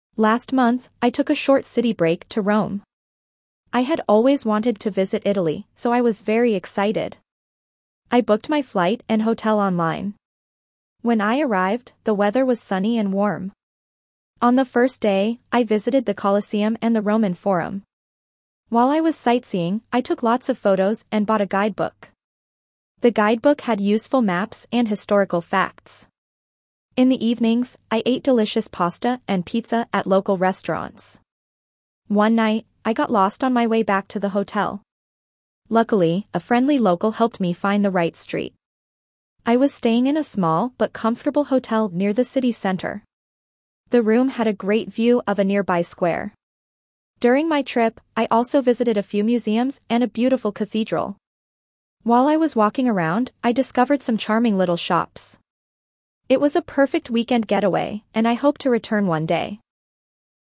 Dictation B1 - City Break
Your teacher will read the passage aloud.